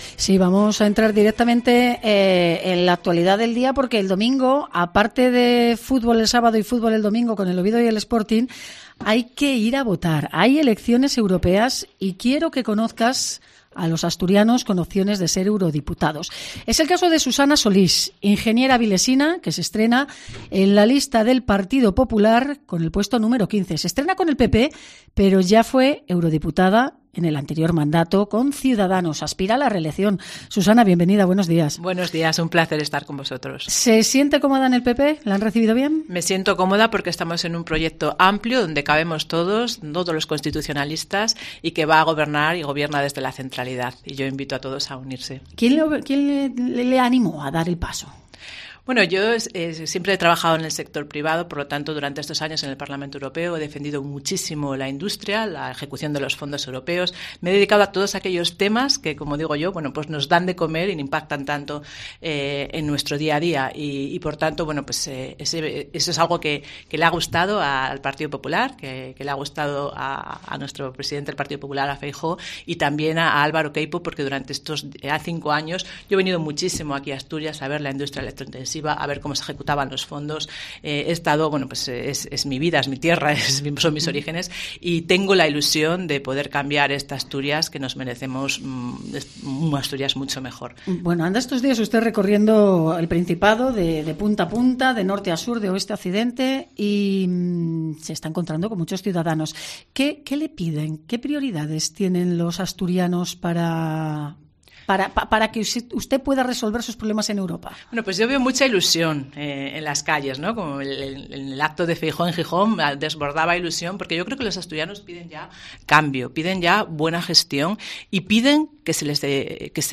Entrevista a Susana Solís (PP) en COPE Asturias